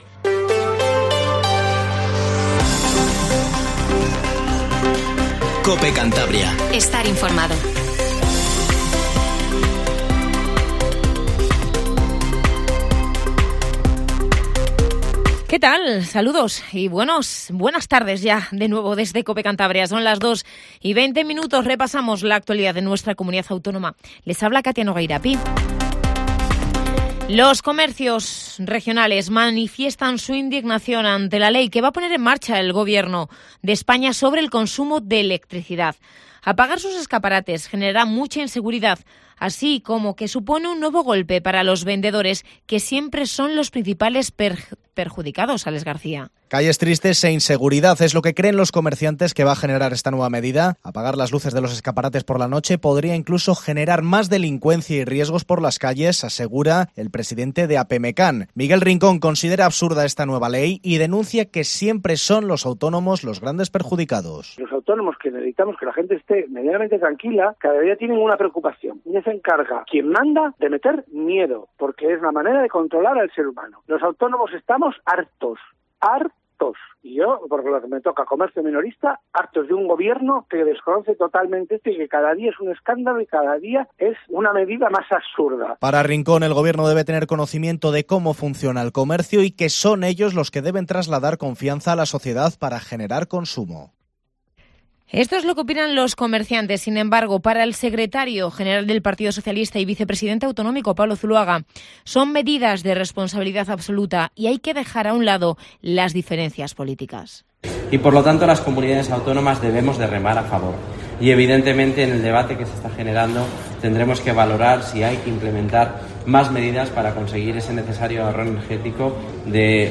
informativo Regional 14:20